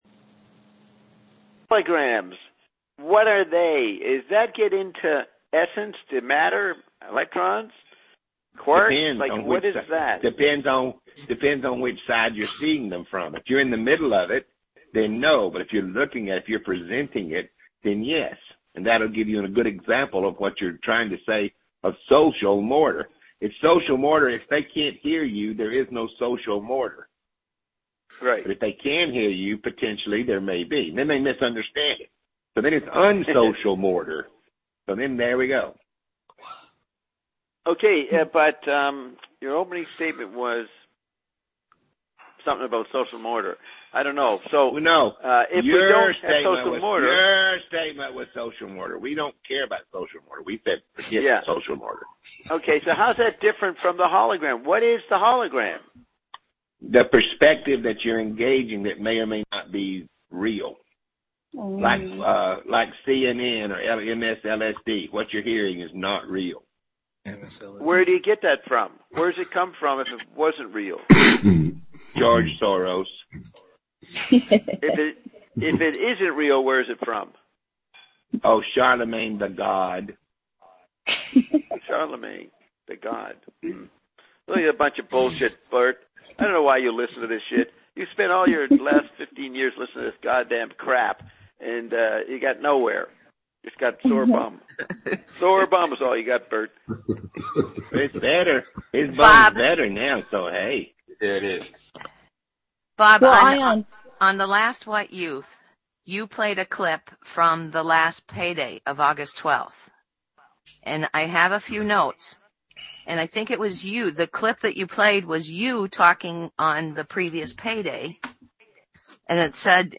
This is perhaps the greatest discussion you will ever listen to… and that’s an understatement.
If you can’t take the ideas and voices, then try the last 58 minutes which presents a sample of my legendary, inspiring DJing.